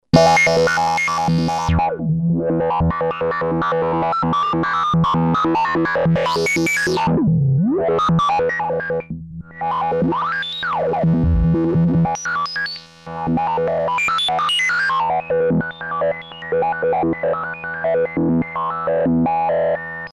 XP: sample and hold test 2
XP-samplehold2.mp3